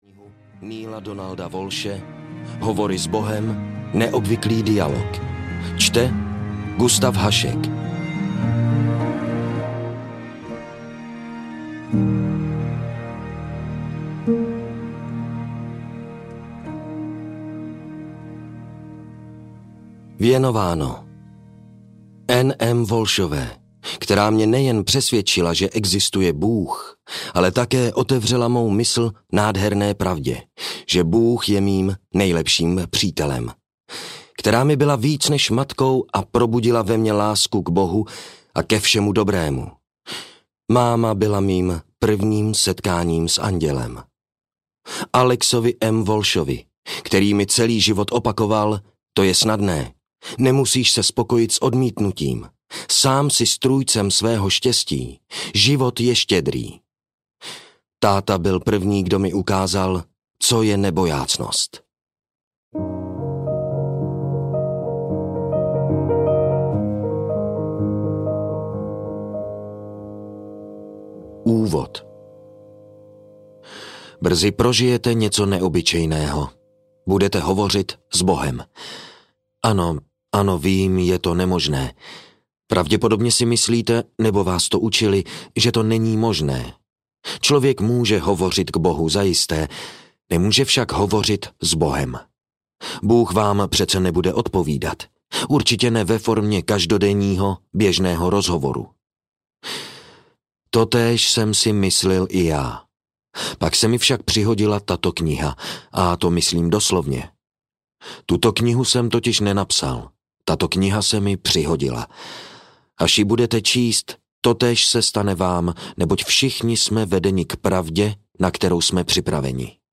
Hovory s Bohem I audiokniha
Ukázka z knihy